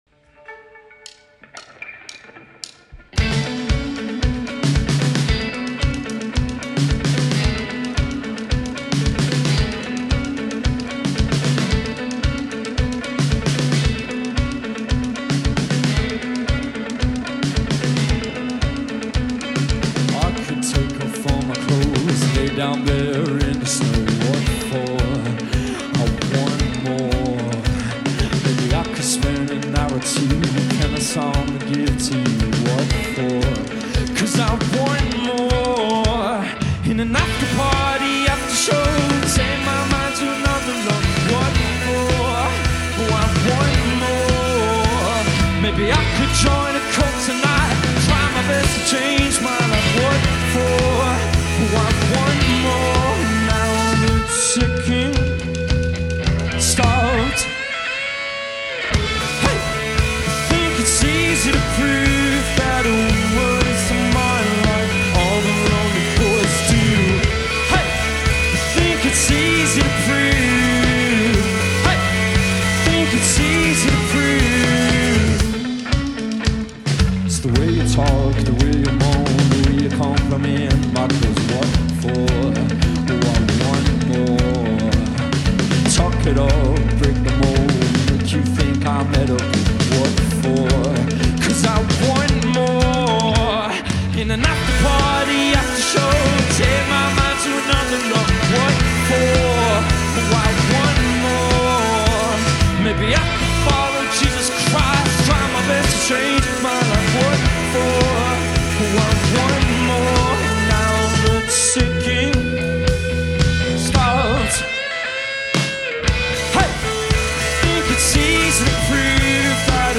lead vocals
drums
lead guitar
bass